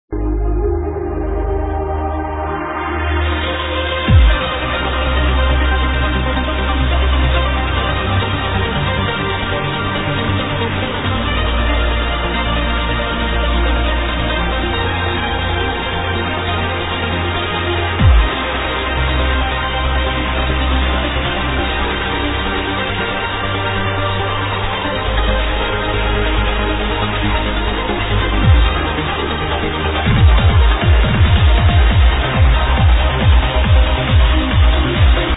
Love What furious trance tune is this??